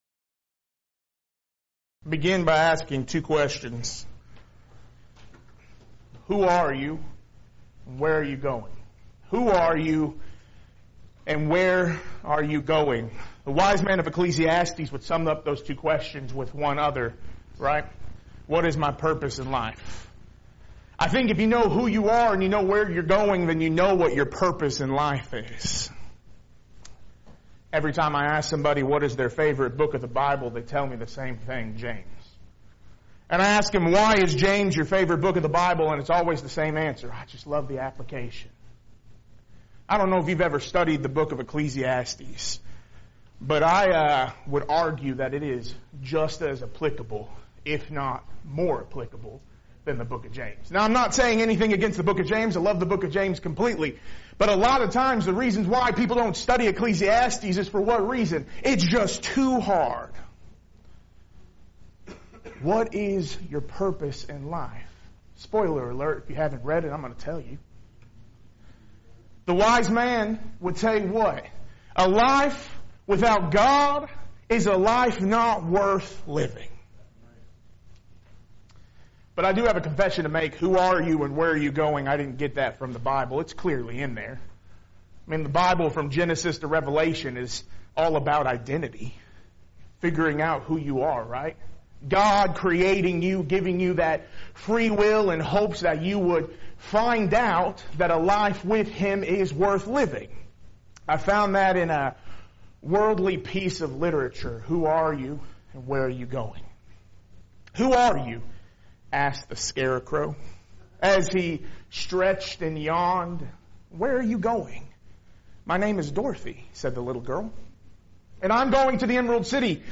Preacher's Workshop